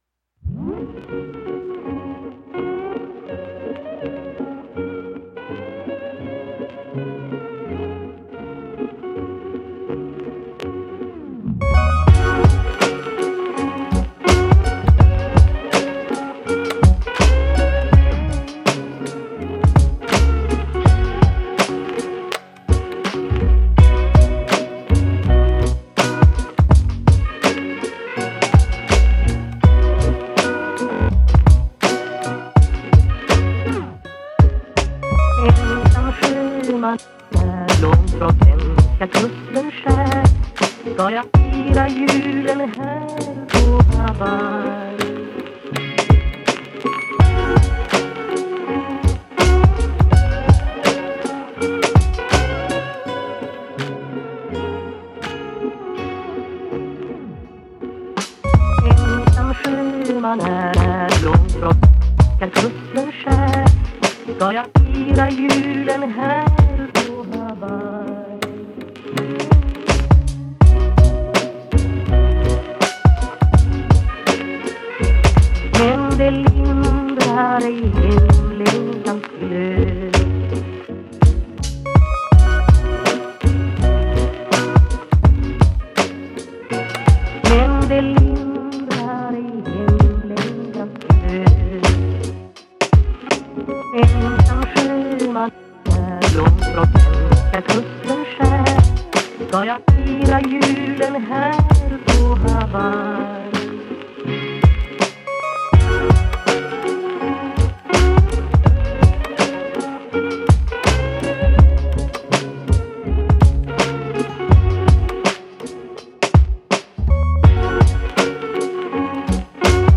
рождественская песня
современная обработка